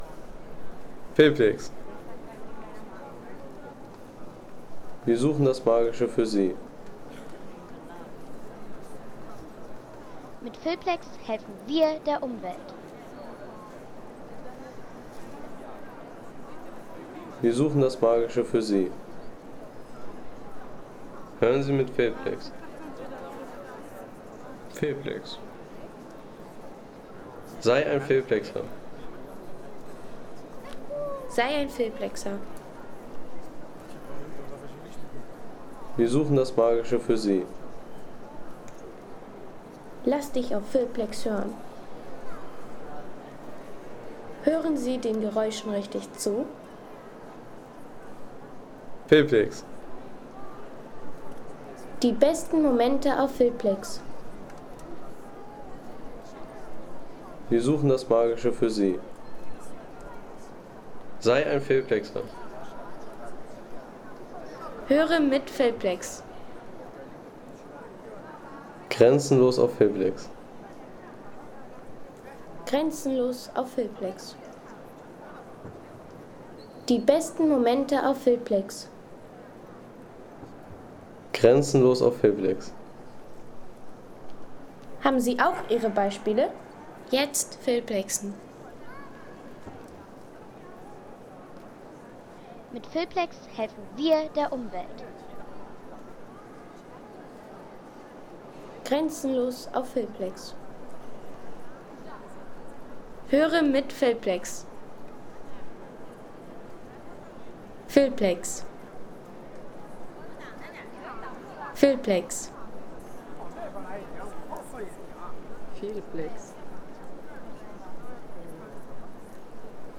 Die Akustik im Petersdom
Die Laute der großen Räumlichkeiten während der Besuchszeit im Pete ... 5,50 € Inkl. 19% MwSt.